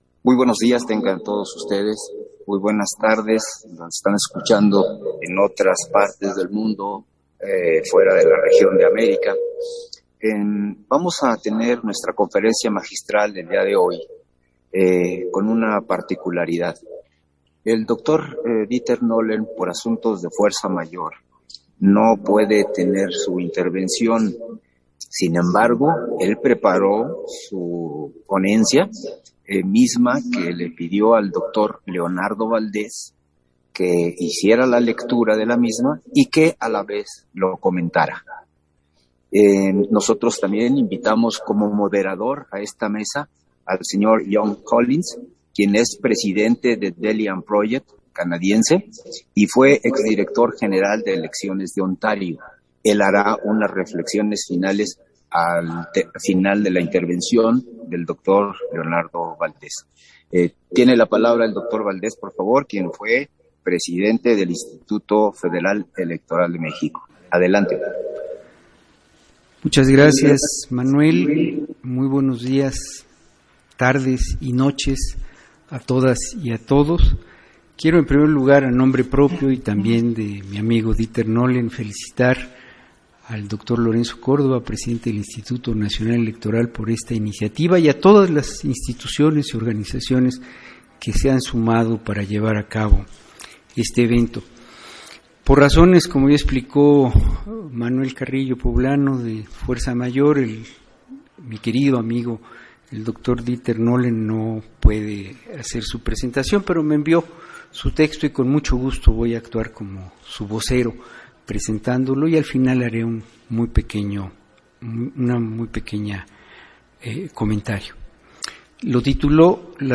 210922_AUDIO_-CONFERENCIA-MAGISTRAL-LAS-NORMAS-Y-LAS-INSTITUCIONES-ELECTORALES
Versión estenográfica de la Conferencia Magistral escrita por Dieter Nohlen y leída por Leonardo Valdéz en el marco de la Cumbre Global de la Democracia Electoral